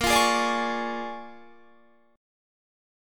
A#7#9 chord